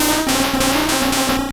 Cri de Noadkoko dans Pokémon Or et Argent.